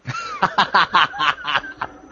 Play HA HA HA - Chines - SoundBoardGuy
ha-ha-ha-chines.mp3